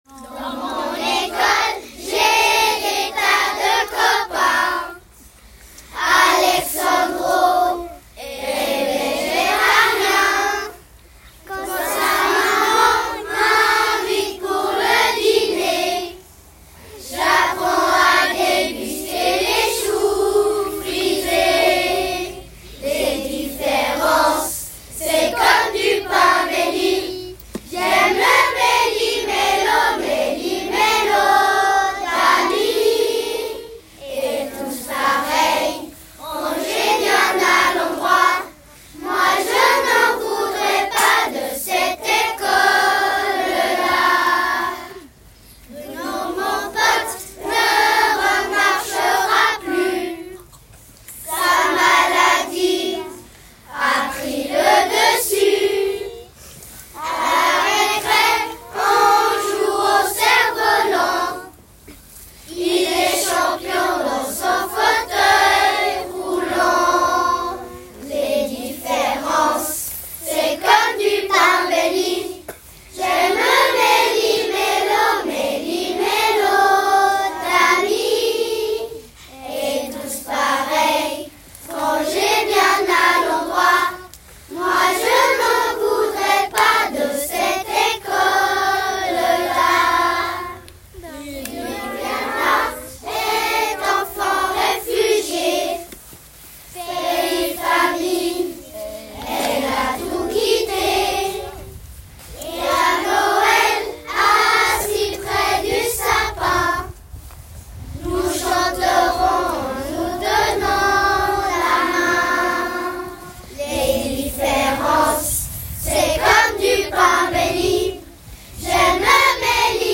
Un chant commun
Cliquez ci-dessous pour écouter le chant interprété par les élèves de 1P à 6P de Rougemont